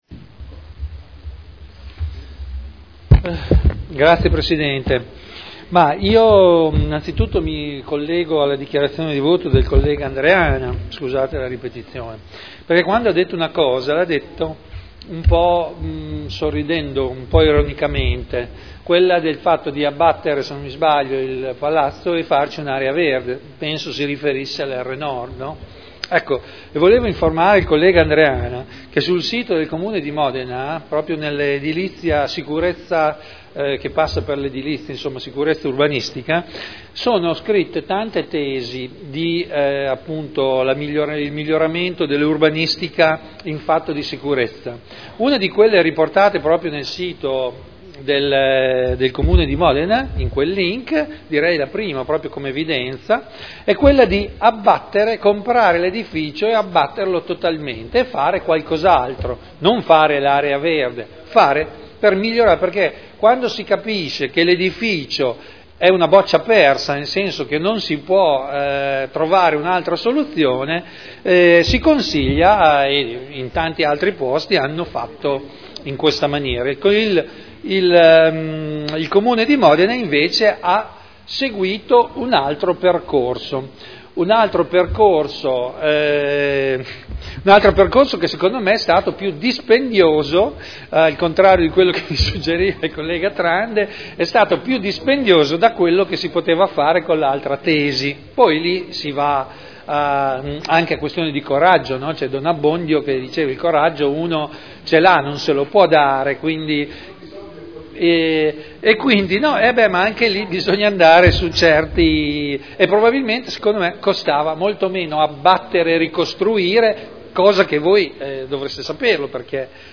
Proposta di deliberazione: Accordo integrativo al programma per la riqualificazione urbana del comparto “Ex Mercato Bestiame” a Modena (art. 9 della L.R. 19/1998). Dichiarazioni di voto